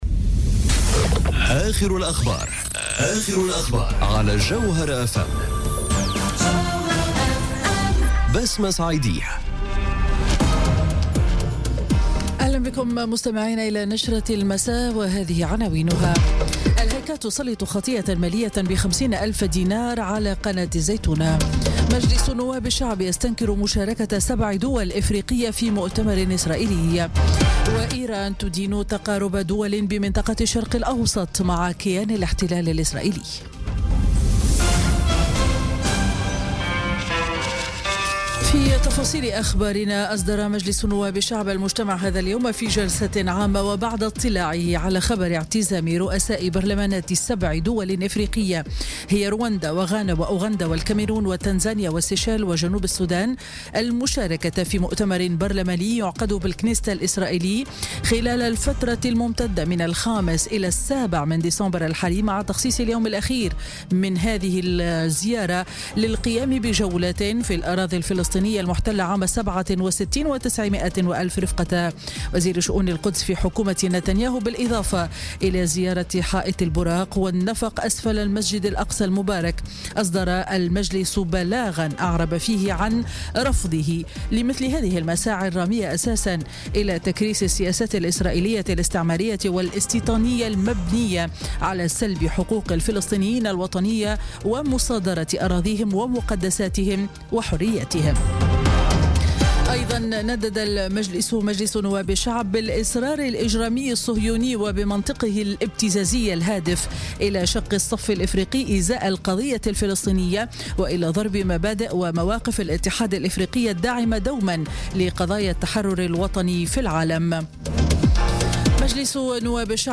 نشرة أخبار السابعة مساءً ليوم الثلاثاء 05 ديسمبر 2017